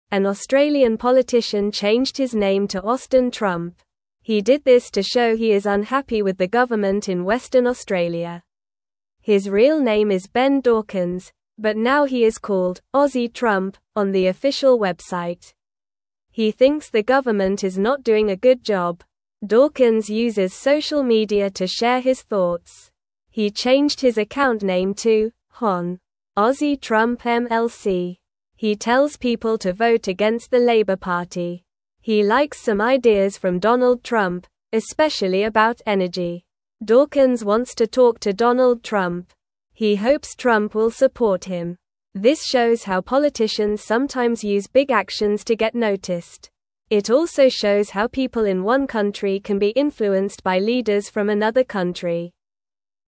Normal
English-Newsroom-Lower-Intermediate-NORMAL-Reading-Man-Changes-Name-to-Austin-Trump-for-Attention.mp3